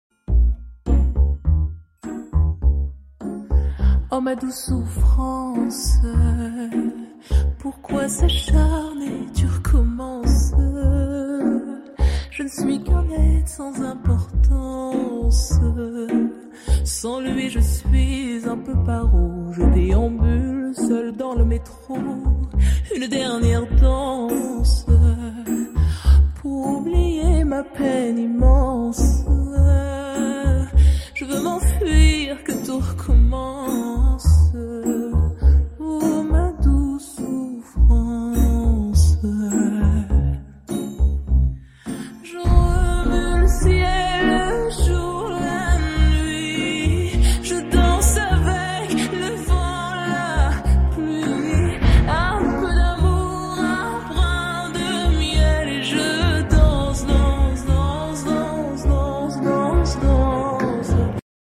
8D sound